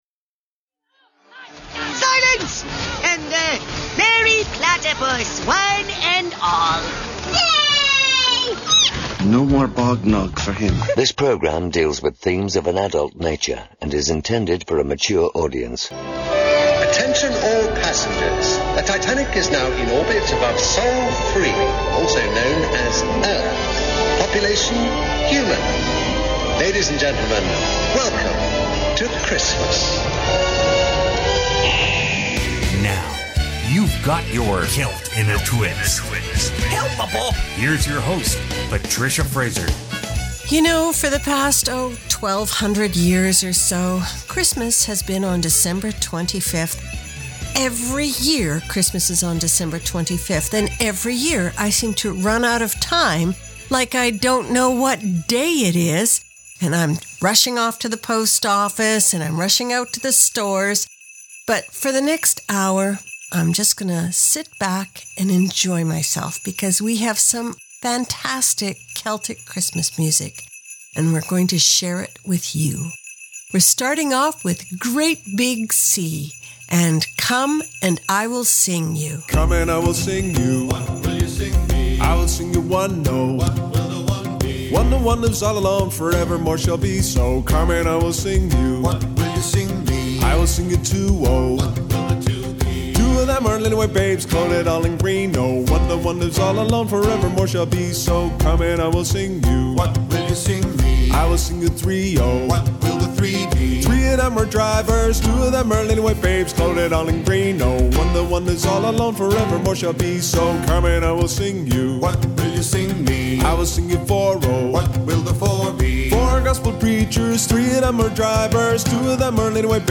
It's a hand-picked hour of our favorite modern holiday Celtic fare.